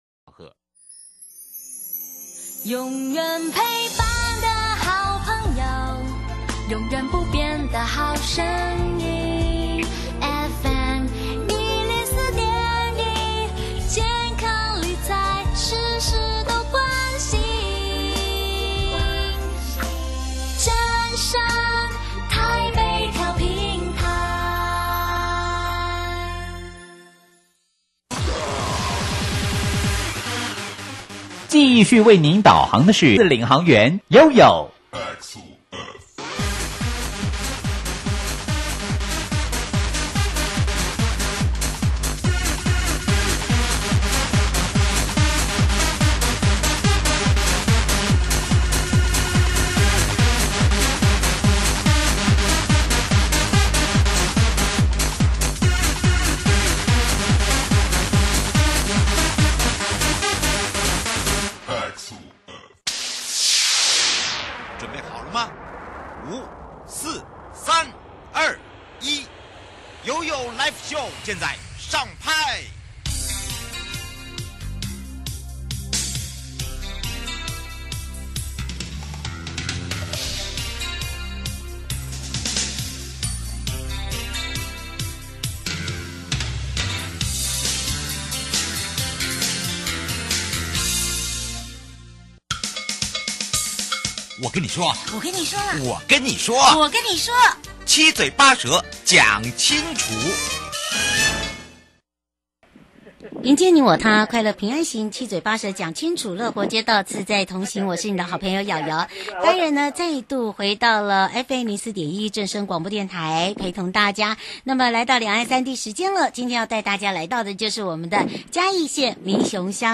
受訪者： 營建你我他-快樂平安行-七嘴八舌講清楚- 中央前瞻提升道路品質計畫對嘉義縣民雄鄉有何助益?民眾對計畫